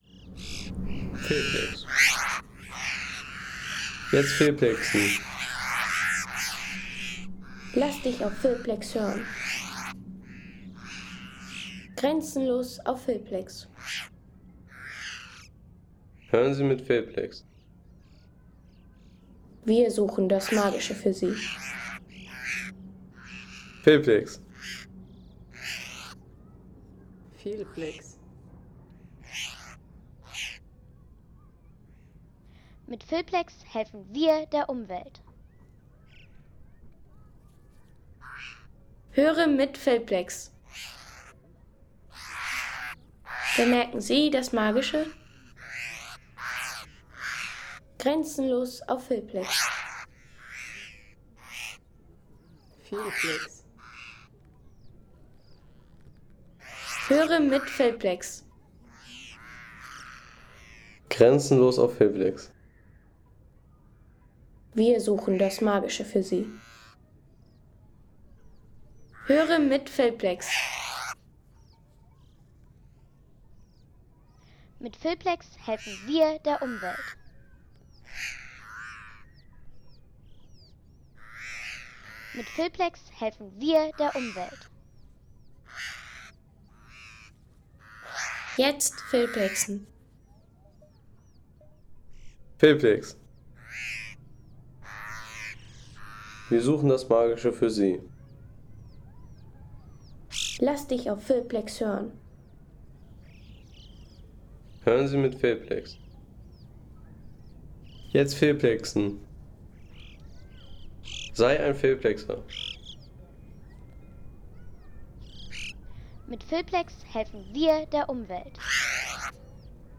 Seefelder Spitze Home Sounds Landschaft Berge Seefelder Spitze Seien Sie der Erste, der dieses Produkt bewertet Artikelnummer: 237 Kategorien: Landschaft - Berge Seefelder Spitze Lade Sound.... Atemberaubende Gipfelerlebnisse auf der Seefelder Königstour.